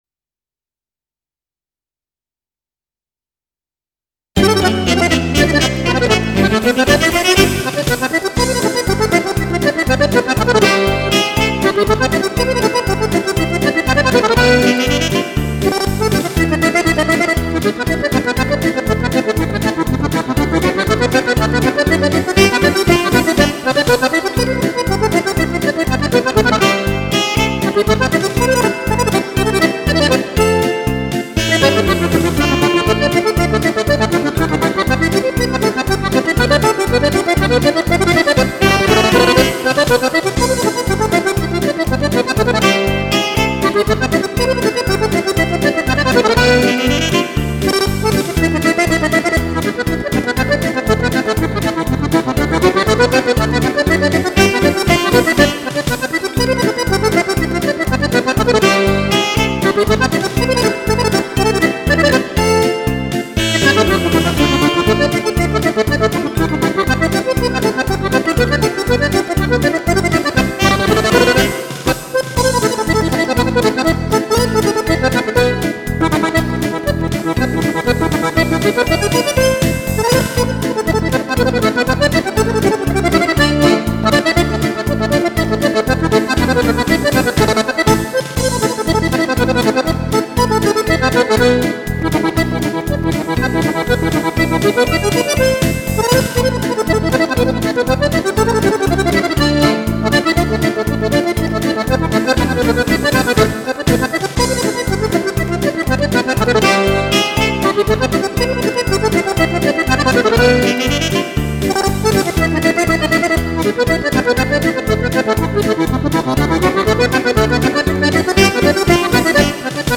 polca